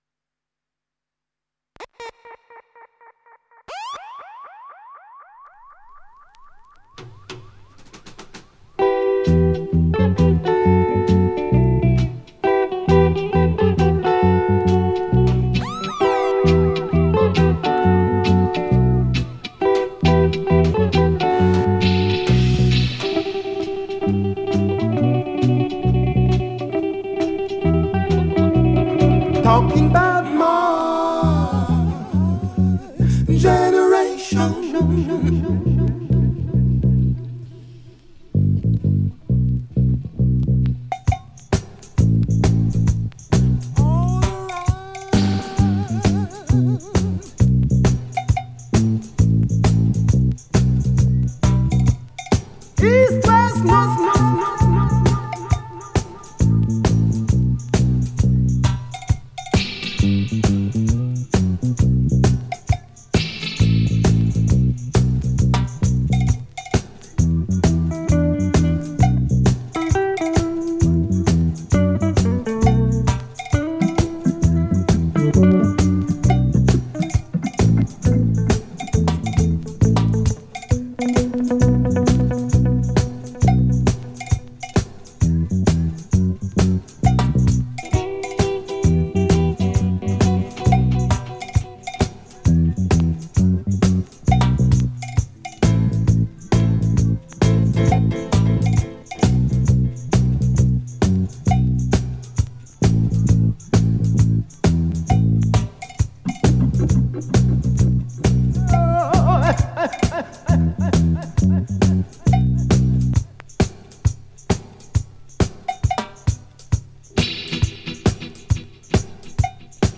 roots meeting